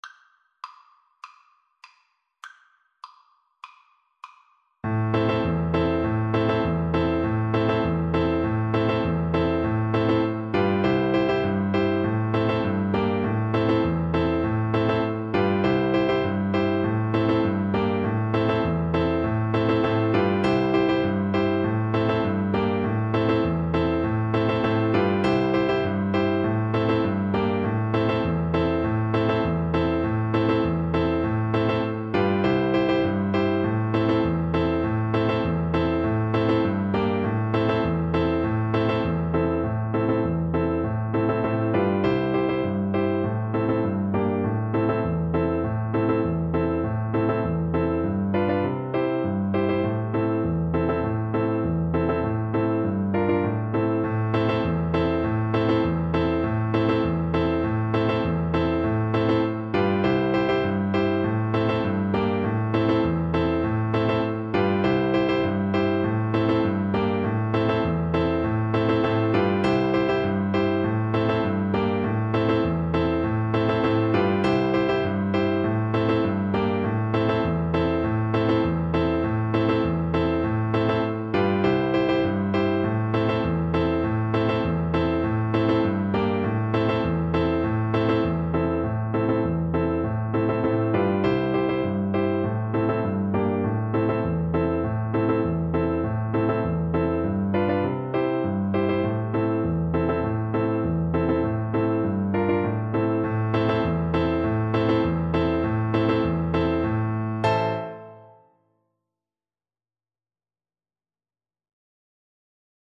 Violin
D minor (Sounding Pitch) (View more D minor Music for Violin )
Moderato
4/4 (View more 4/4 Music)
World (View more World Violin Music)